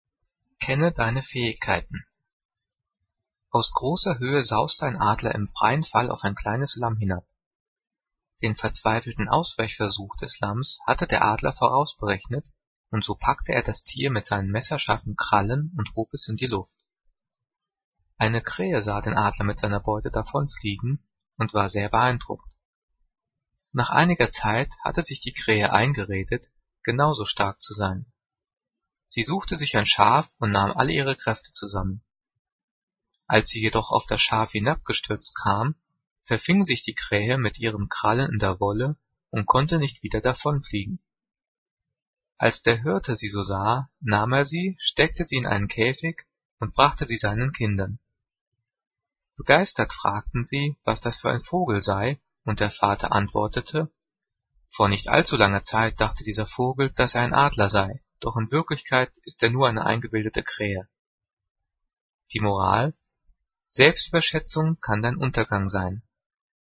Diktat: "Kenne deine Fähigkeiten" - 5./6. Klasse - Getrennt- und Zus.
Gelesen:
gelesen-kenne-deine-faehigkeiten.mp3